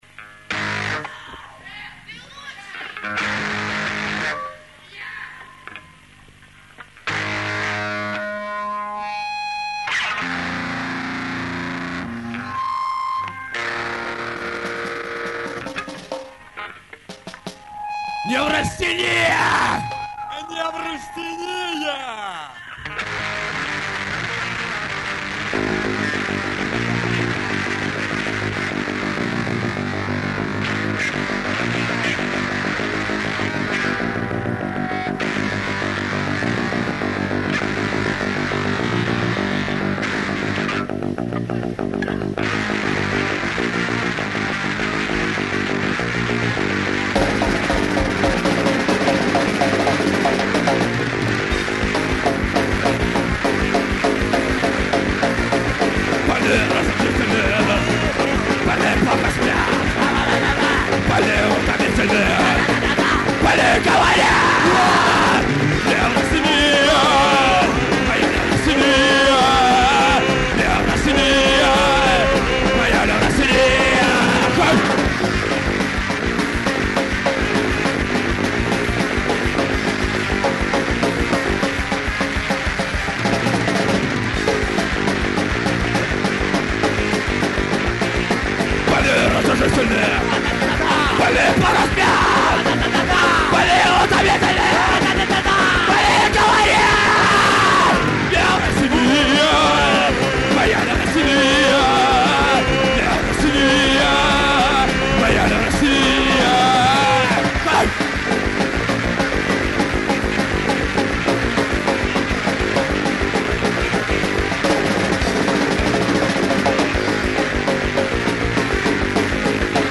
Концерт 18.02.00 в ЦеЛКе в Двинске.